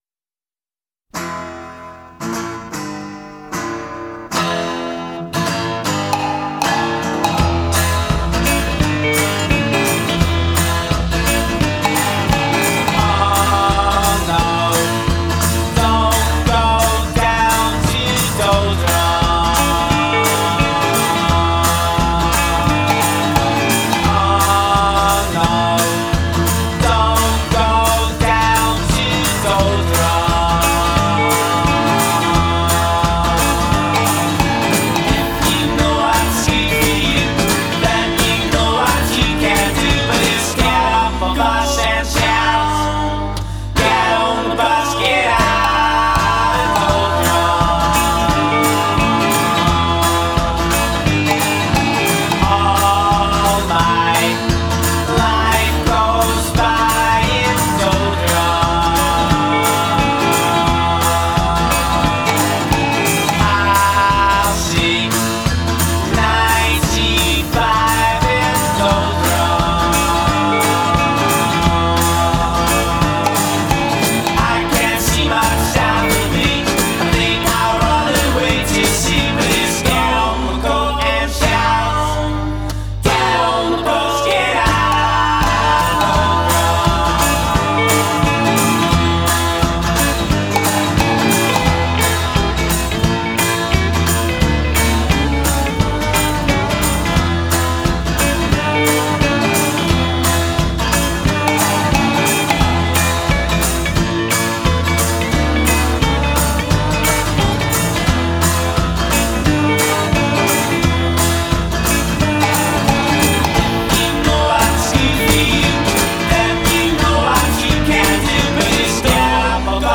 the freewheeling acoustic blues